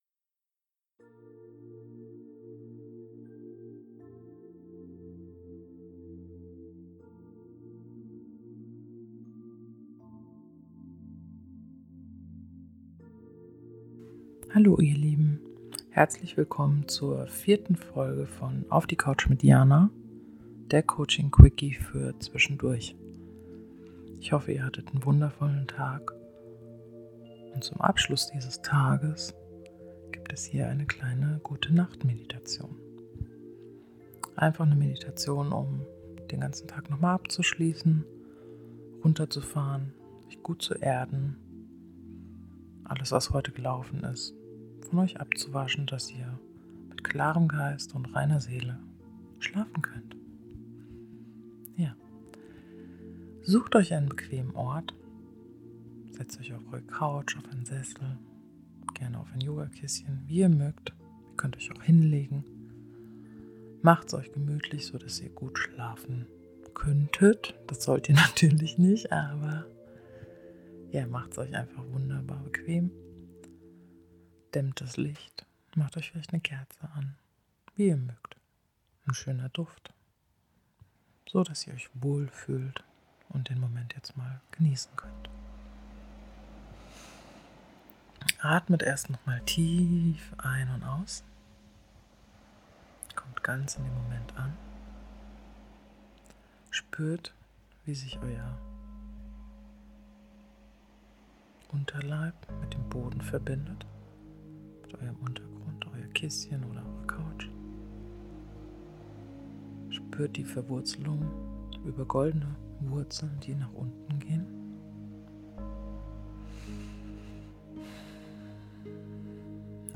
In der viertel Folge lade ich euch ein zu einer GUTE NACHT MEDITATION
pod4-gute_NACHT-Medi.mp3